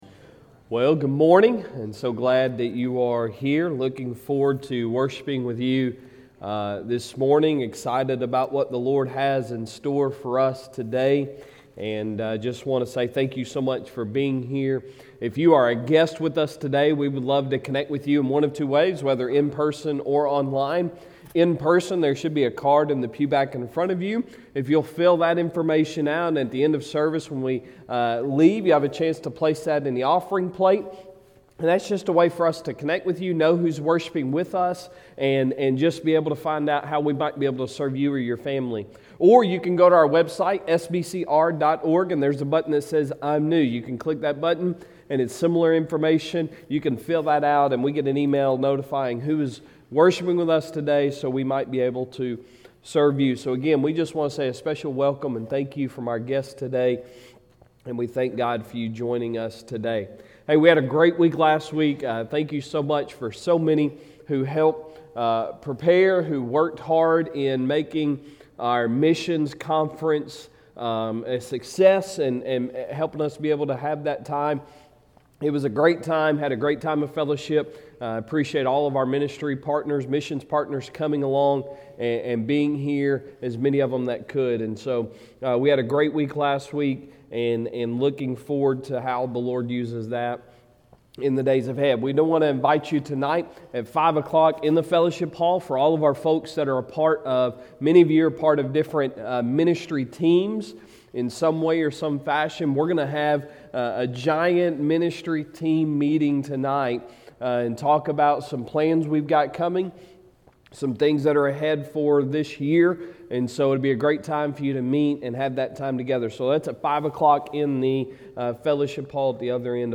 Sunday Sermon February 6, 2022